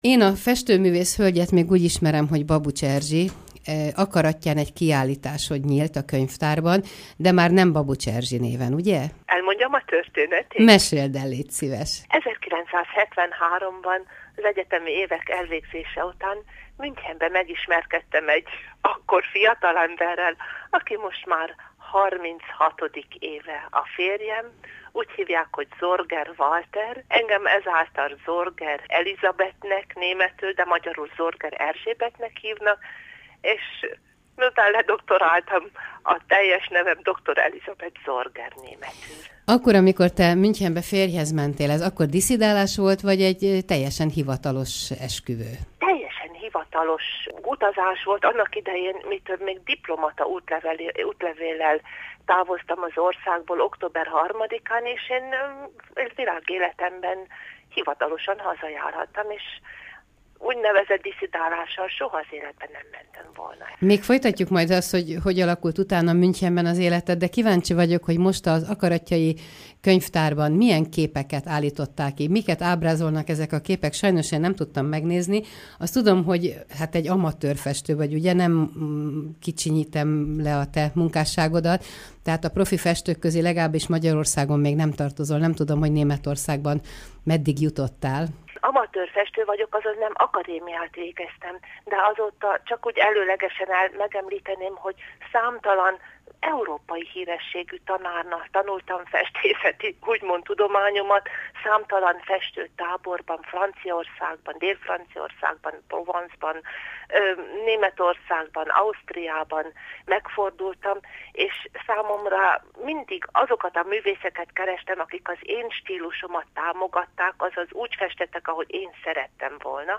Inteview